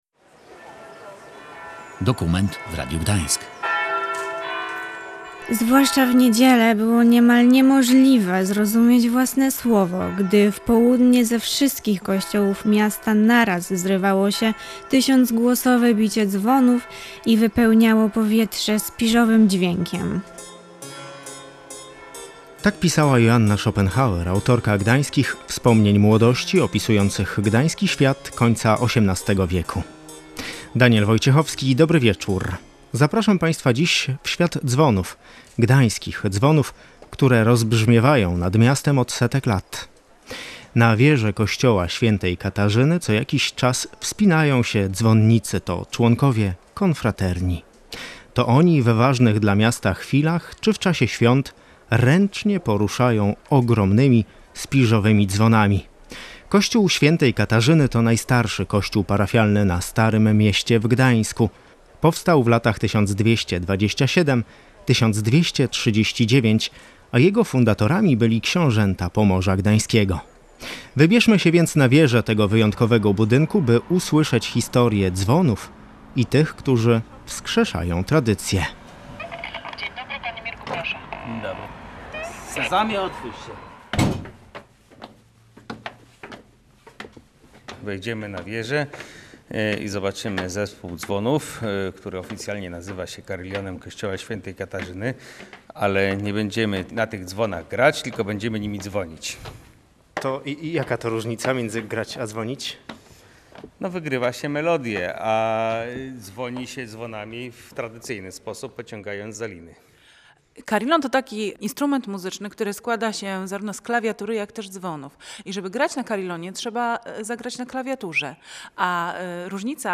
Wybraliśmy się na wieżę tego wyjątkowego budynku, by usłyszeć historię historię dzwonów i tych, którzy wskrzeszają tradycję: